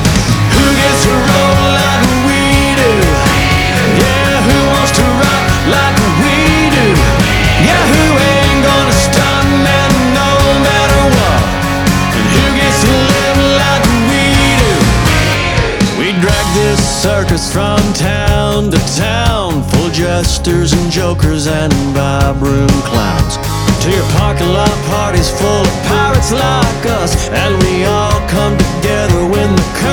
Genre: Country